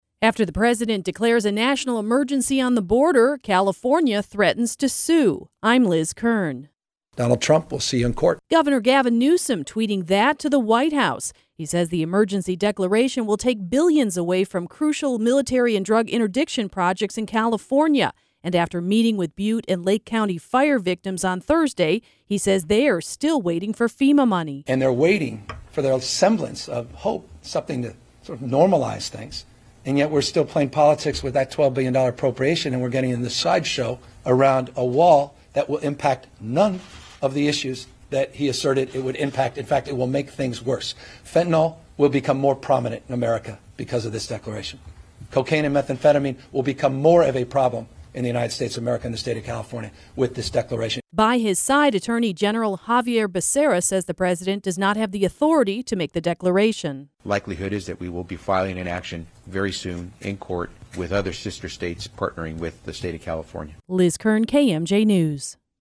Speaking Friday morning at the California State Capitol, Gov. Gavin Newsom said President Trump’s declaration of a national emergency on the U.S. border with Mexico is not a true emergency.